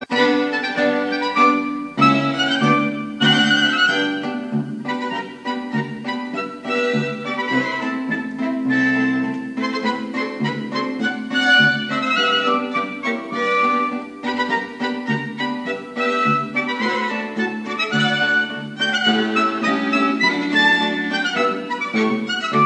Aufgenommen am 1. - 2. Mai 2000 in Wien